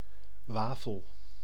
Ääntäminen
Synonyymit oblie Ääntäminen Tuntematon aksentti: IPA: /ˈʋaːfəl/ Haettu sana löytyi näillä lähdekielillä: hollanti Käännös Ääninäyte Substantiivit 1. wafer US 2. waffle US Suku: f .